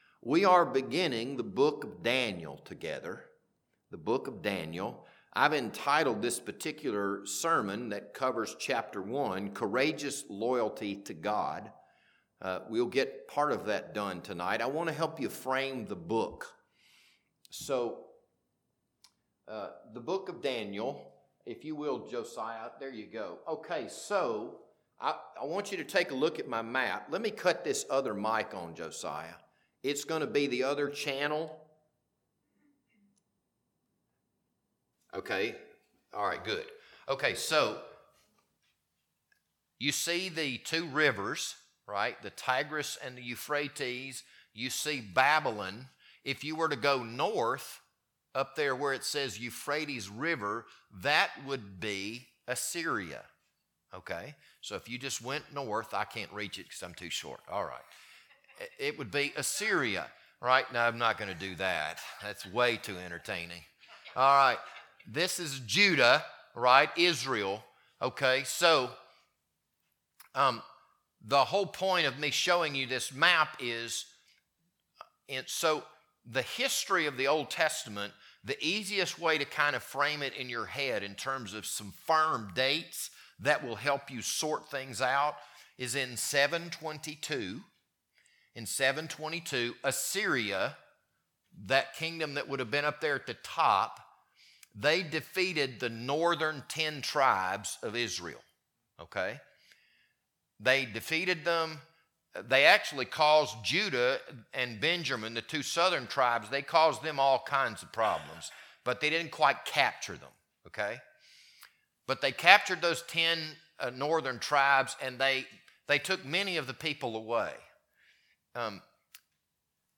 This Sunday evening sermon was recorded on April 12th, 2026.